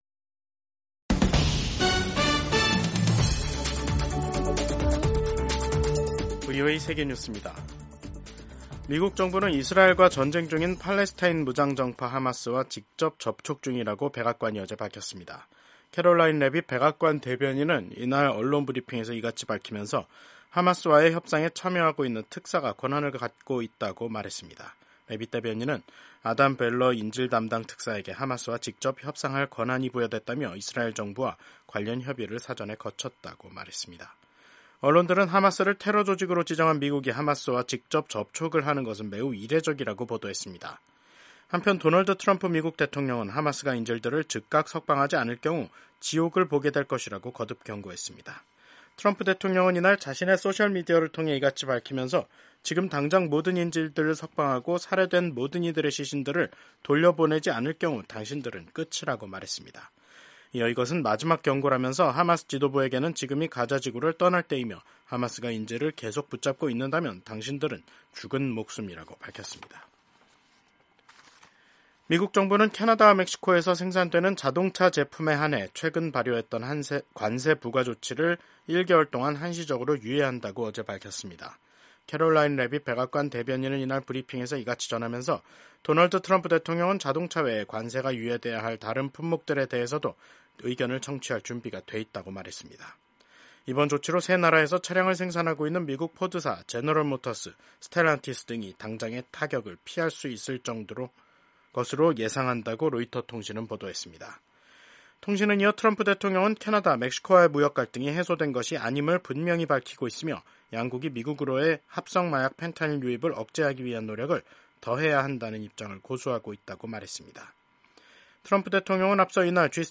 세계 뉴스와 함께 미국의 모든 것을 소개하는 '생방송 여기는 워싱턴입니다', 2025년 3월 6일 저녁 방송입니다. 도널드 트럼프 미국 대통령이 멕시코와 캐나다산 자동차에 대한 관세 부과를 한 달 연기하기로 했습니다. 미국이 우크라이나에 대한 정보 공유를 중단했습니다.